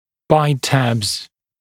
[b a ɪt tæbz]